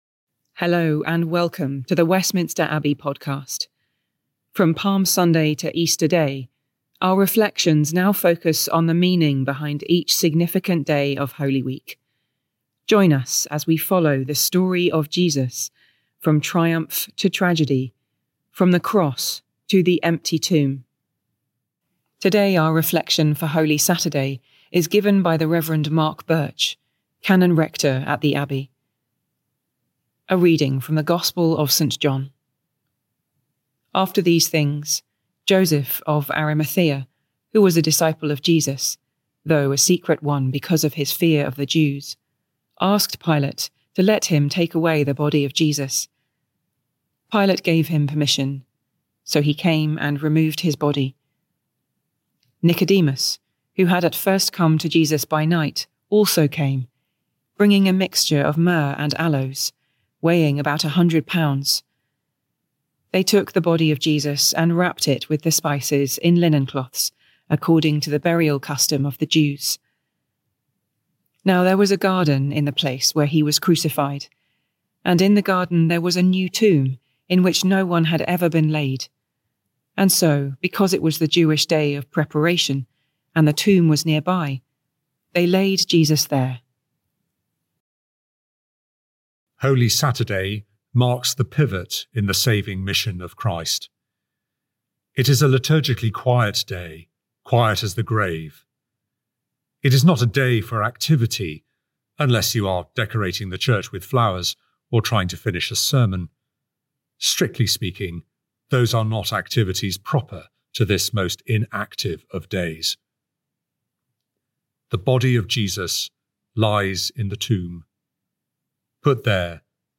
A Holy Saturday Reflection